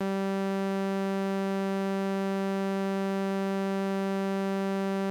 Принесли мне на студию "странный" микрофон.
Работает как через USB (встроенный АЦП) так и позволяет работать с XLR, чем меня и заинтересовал.